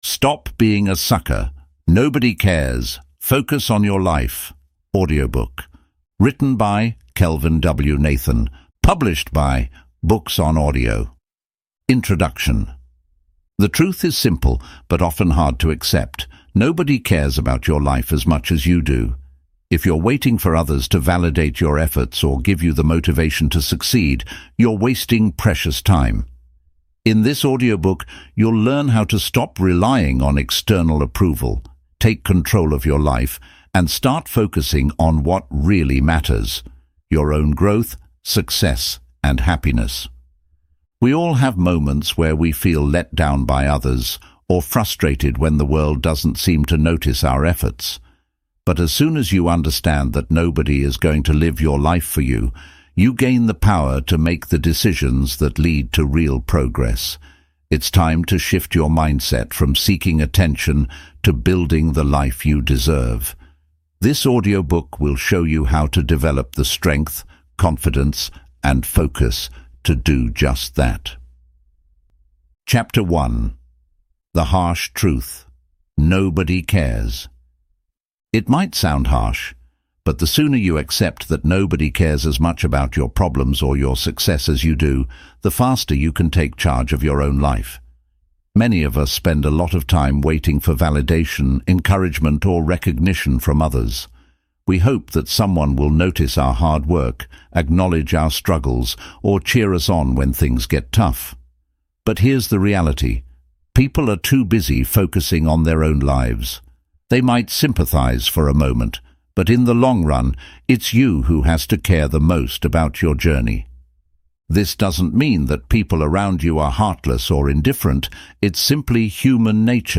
Stop Being a Sucker: Take Control of Your Life Today (Audiobook)